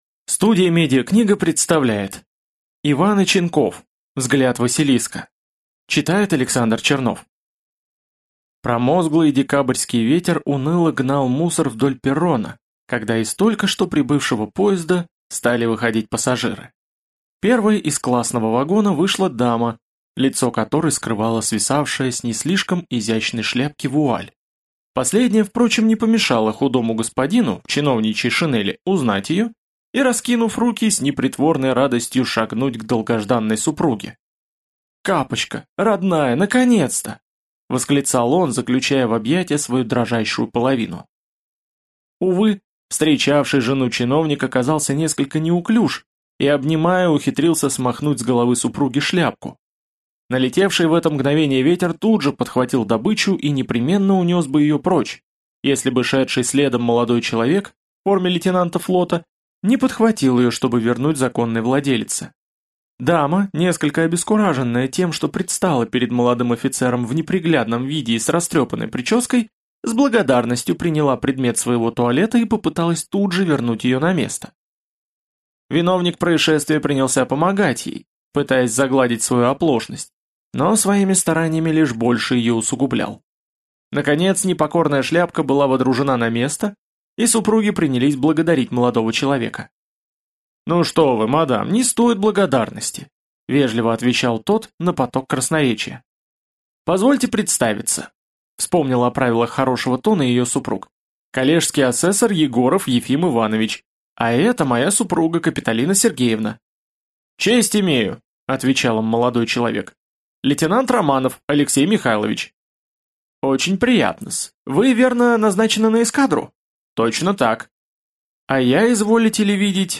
Аудиокнига Взгляд василиска | Библиотека аудиокниг
Прослушать и бесплатно скачать фрагмент аудиокниги